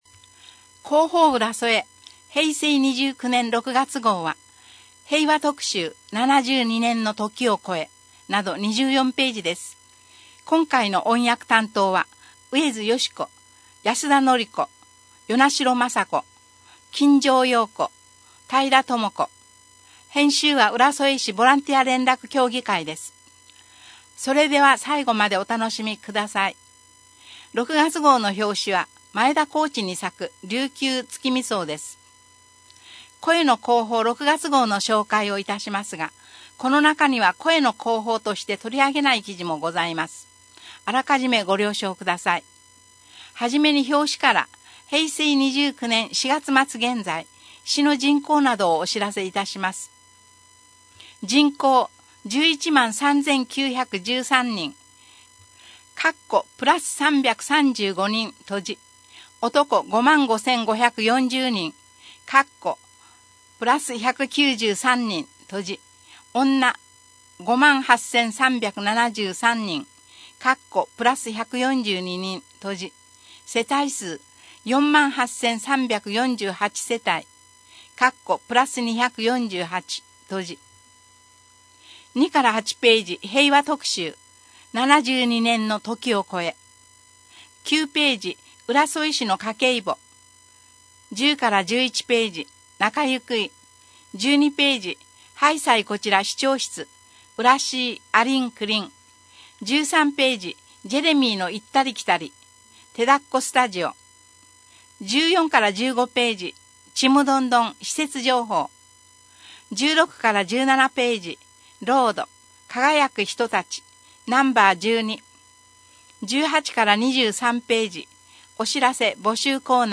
声の広報 「応報うらそえ」を朗読したものを音声データ化しています。